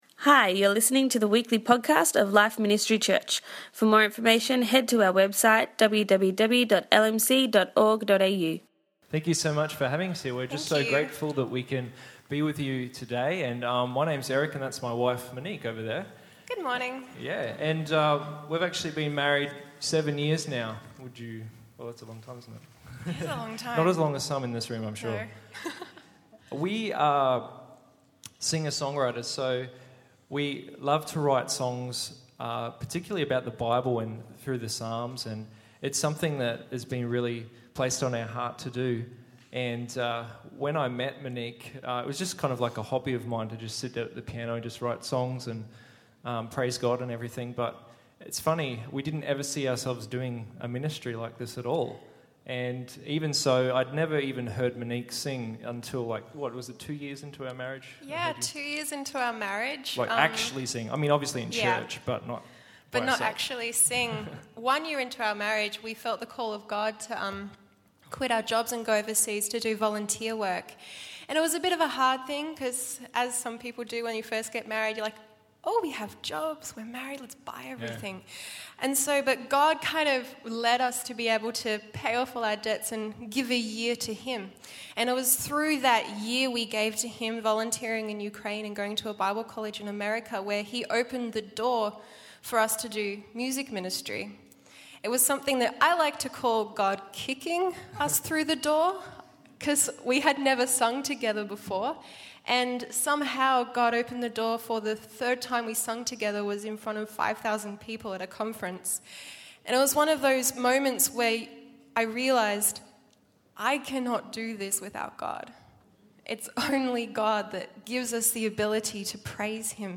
for a beautiful morning of music